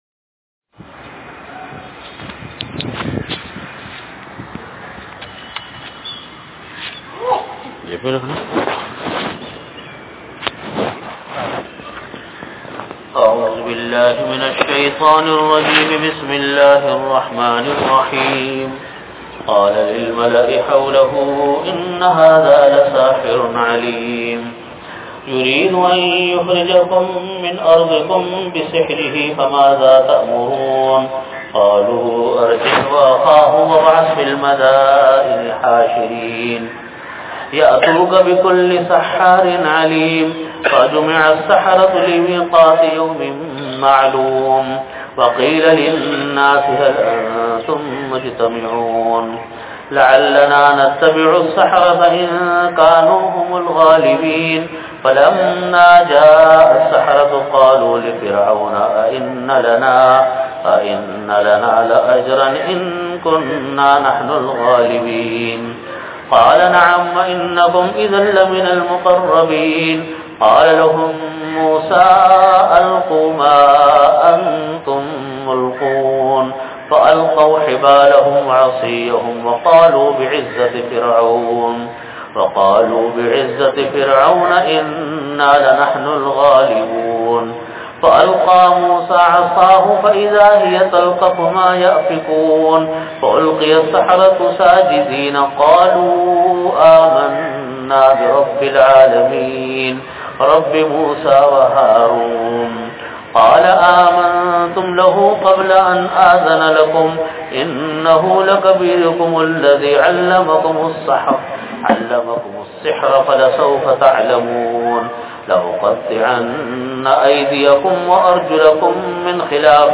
Audio Category: Tafseer
Time: After Asar Prayer Venue: Jamia Masjid Bait-ul-Mukkaram, Karachi